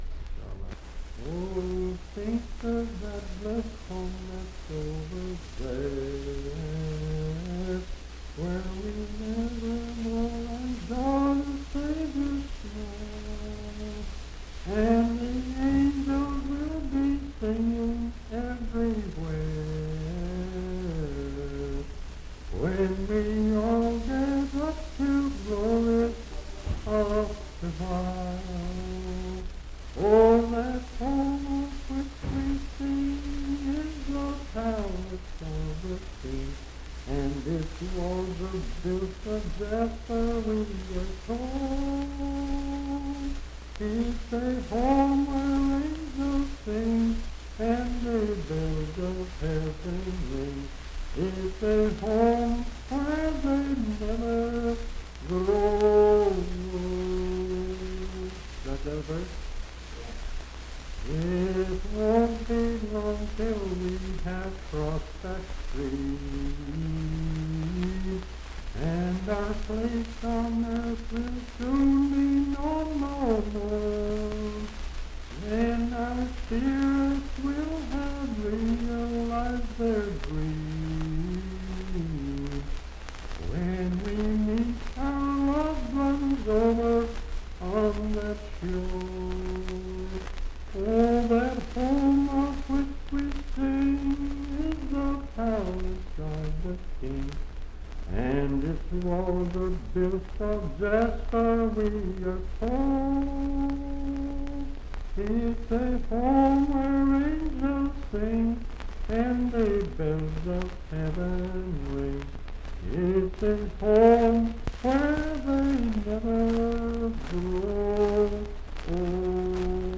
Unaccompanied vocal music
Hymns and Spiritual Music
Voice (sung)
Pocahontas County (W. Va.)